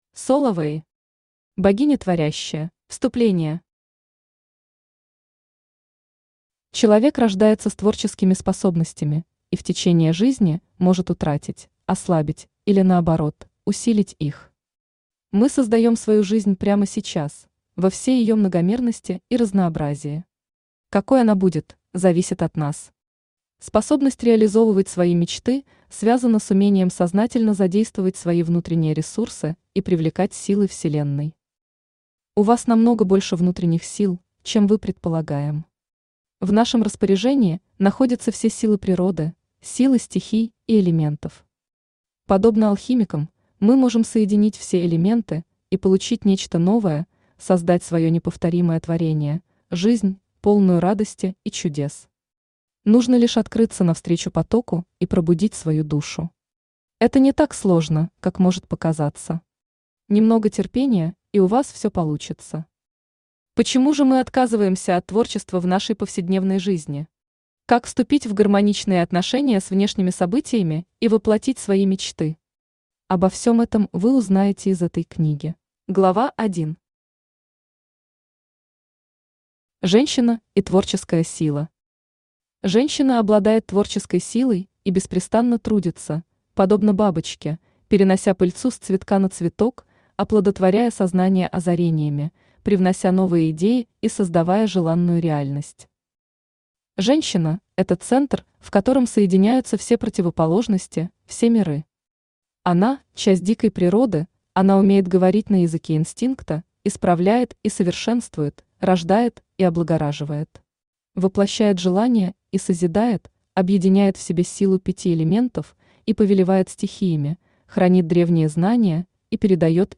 Аудиокнига Богиня творящая | Библиотека аудиокниг
Aудиокнига Богиня творящая Автор Соло Вей Читает аудиокнигу Авточтец ЛитРес.